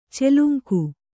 ♪ celuŋku